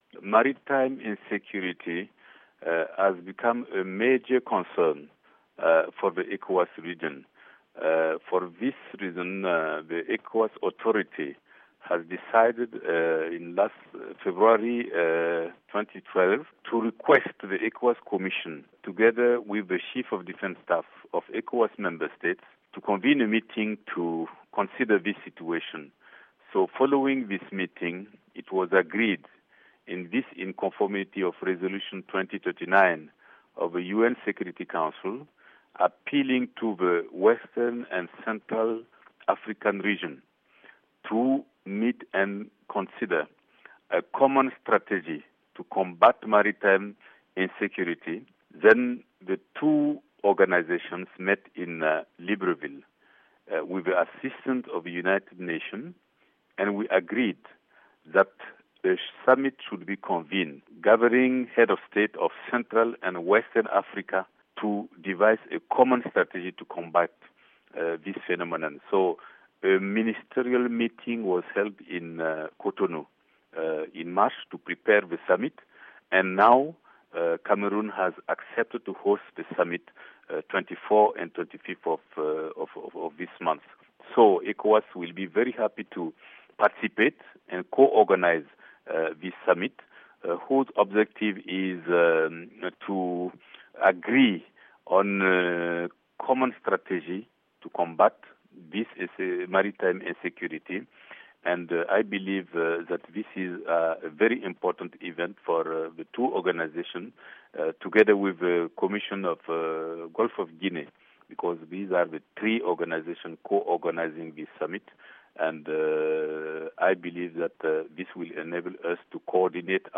interview with Ambassador Kadre Desire Ouedraogo, ECOWAS